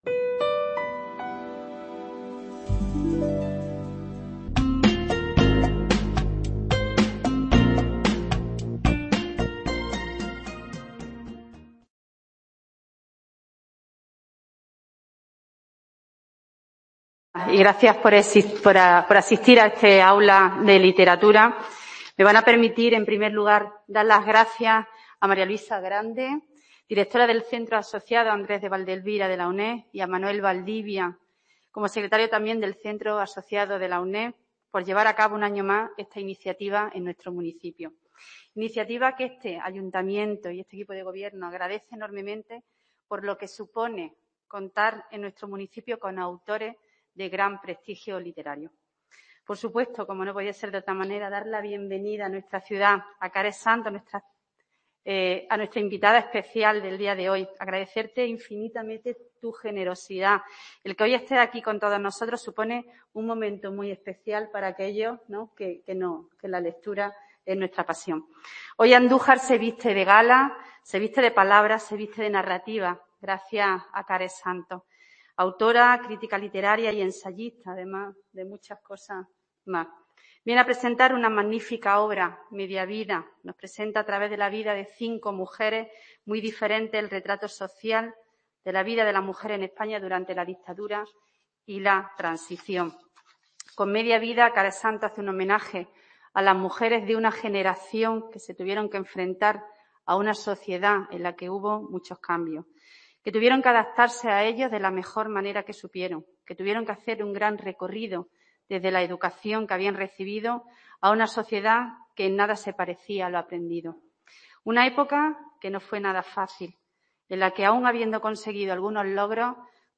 Encuentro literario en Andújar con la escritora Care Santos, que presenta su libro "Media vida", dentro de la V edición del AULA DE LITERATURA que el Centro Asociado de la UNED “Andrés de Vandelvira” de la provincia de Jaén organiza en colaboración con la Diputación Provincial de Jaén y los Ayuntamientos de Alcalá la Real, Andújar, Linares, Jaén y Úbeda.